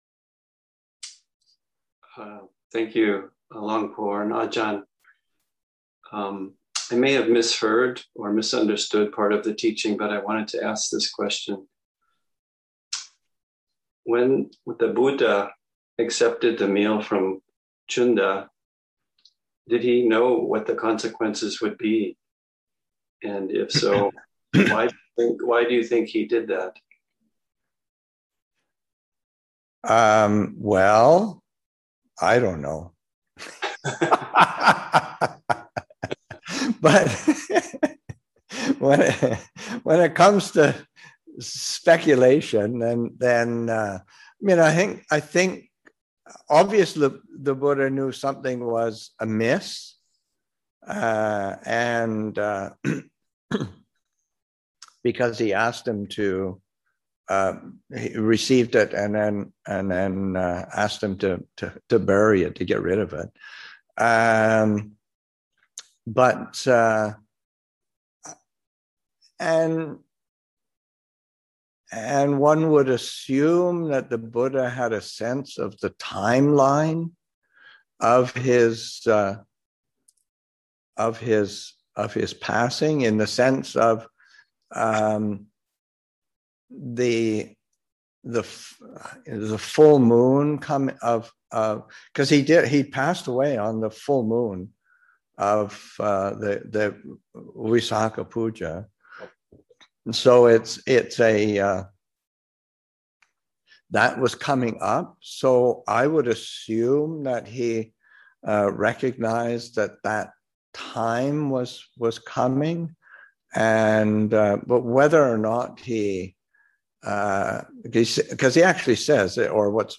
Online from Abhayagiri Buddhist Monastery in Redwood Valley, California